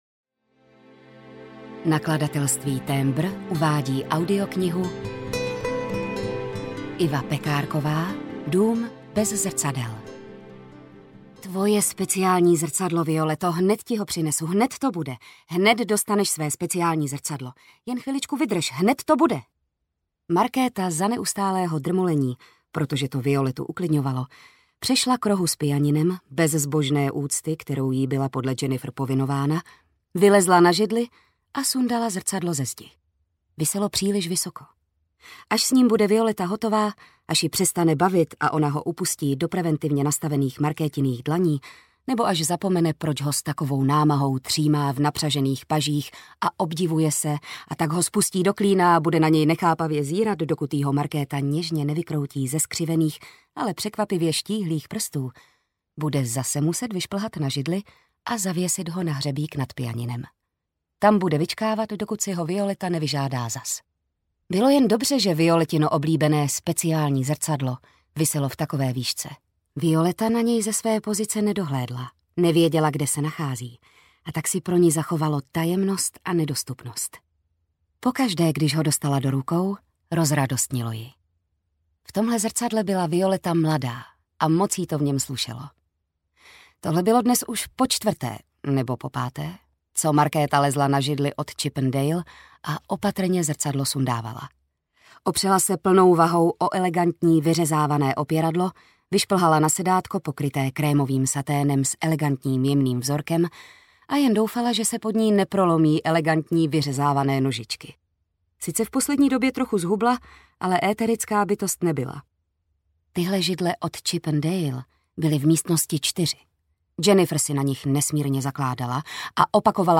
Dům bez zrcadel audiokniha
Ukázka z knihy
• InterpretJitka Ježková